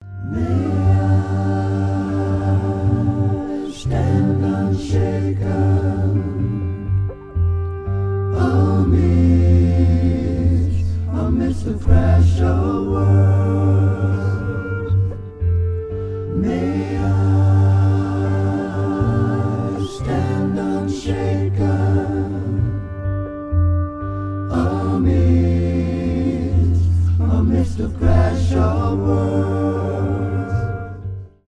alternative , кантри